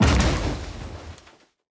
car-stone-impact-5.ogg